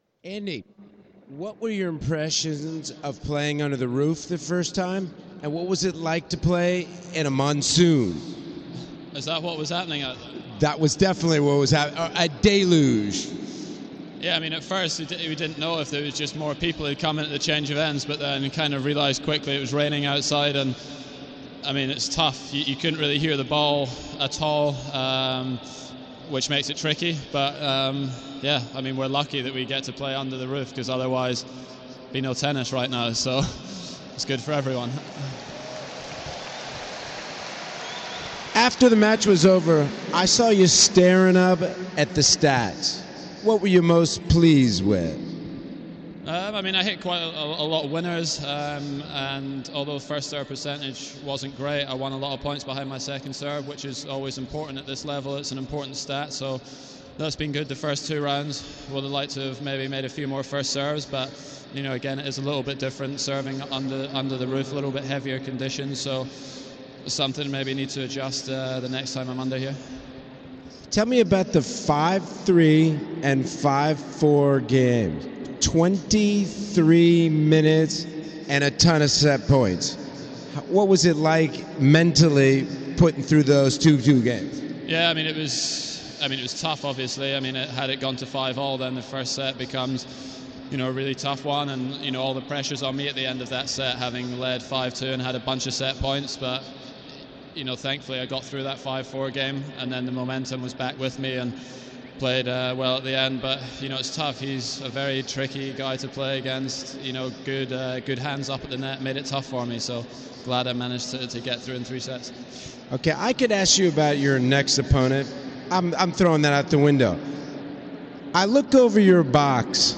Murray's post-match interview